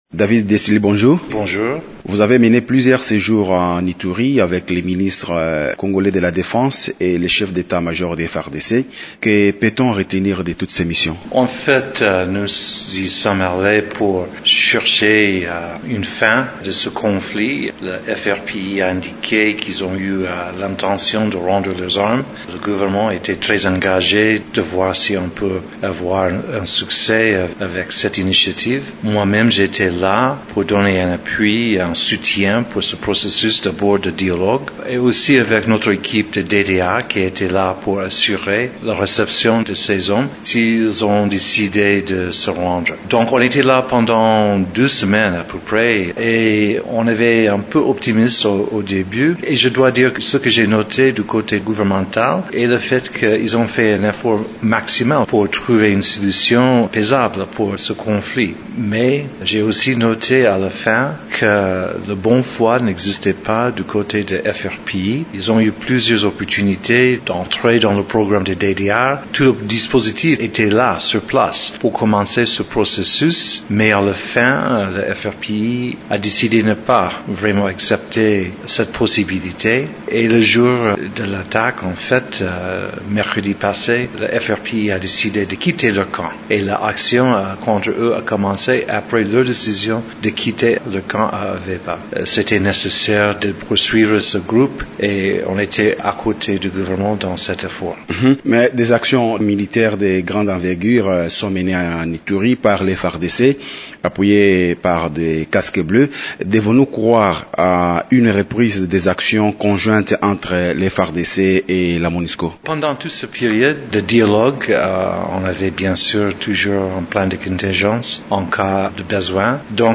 David Gressly s’exprime sur la situation sécuritaire en Ituri